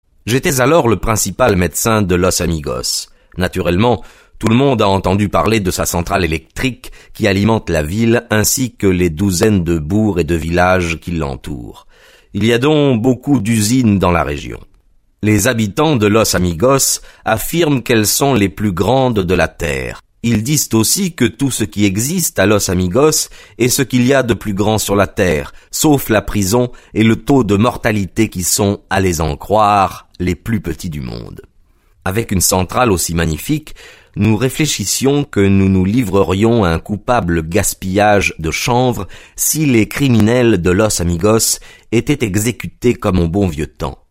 Extrait gratuit - Le fiasco de los amigos de Arthur Conan Doyle